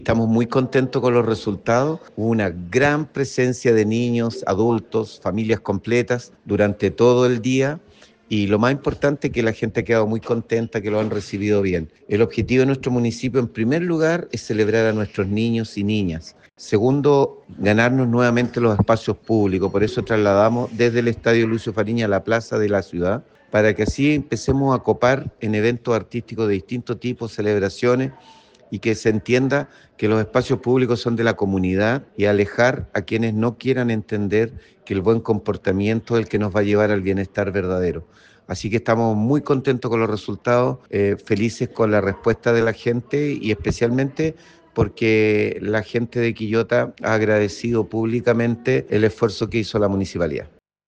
CUNA-ALCALDE-LUIS-MELLA-DIA-DE-LA-NINEZ.mp3